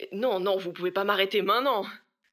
VO_ALL_Interjection_09.ogg